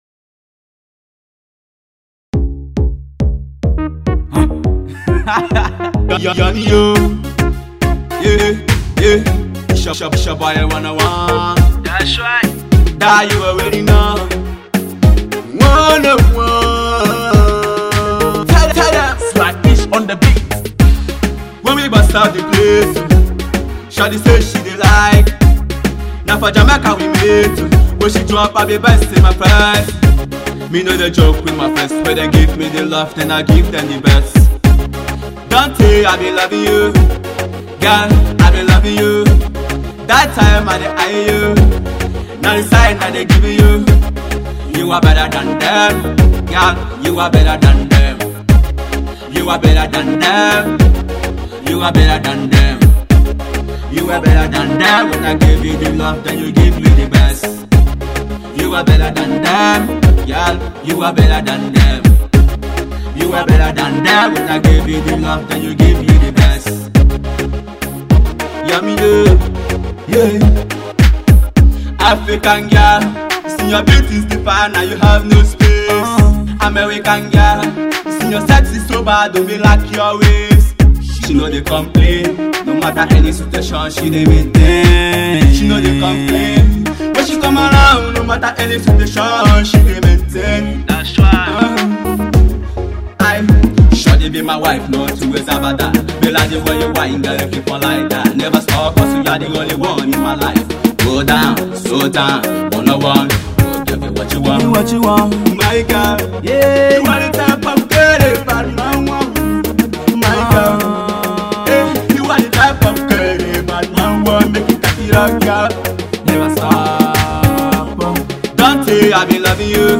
Music Duo